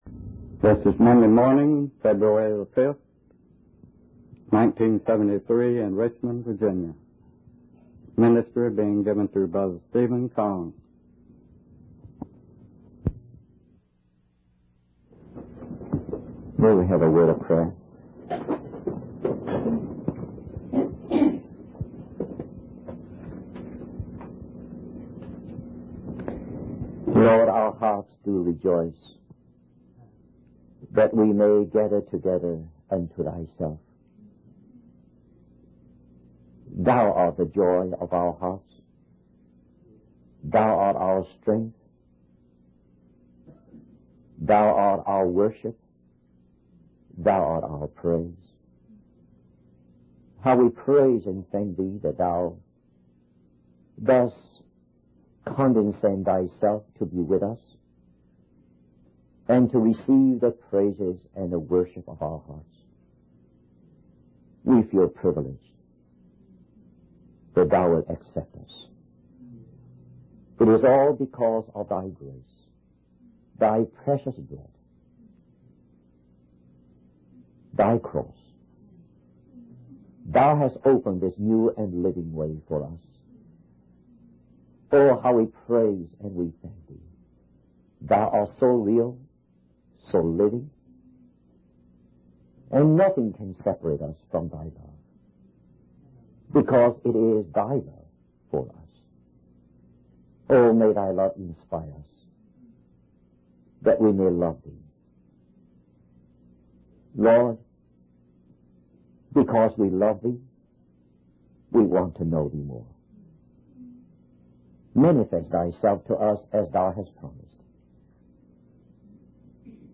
In this sermon, the preacher emphasizes the importance of believing in God's ability to transform and change people. He encourages the audience to have faith in God's power to bring about transformation in individuals.